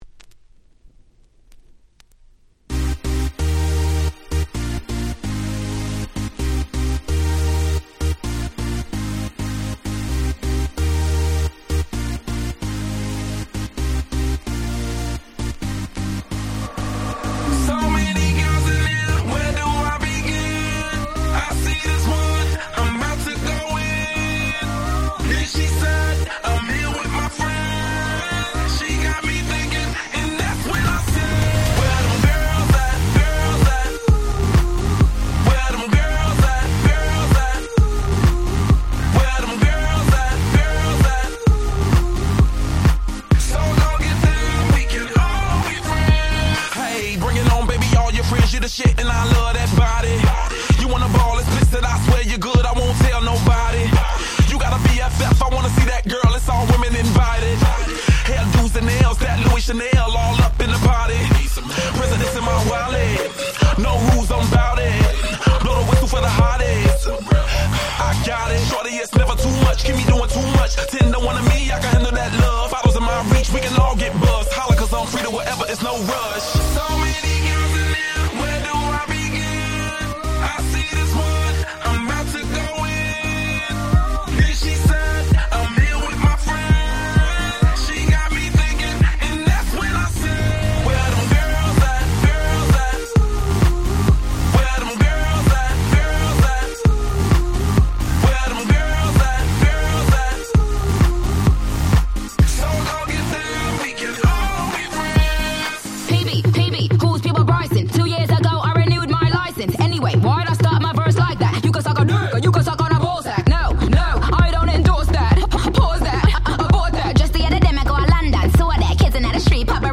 11' Smash Hit EDM / Pops / R&B !!